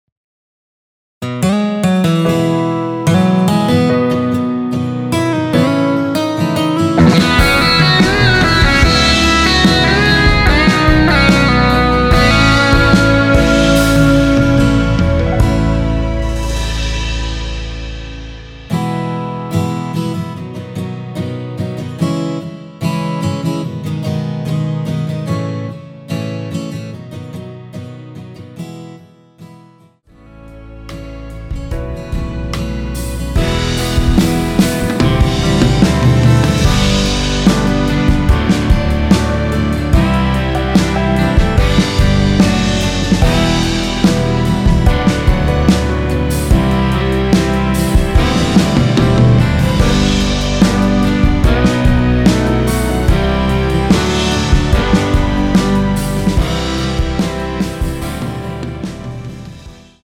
원키에서(-3)내린 MR입니다.
앞부분30초, 뒷부분30초씩 편집해서 올려 드리고 있습니다.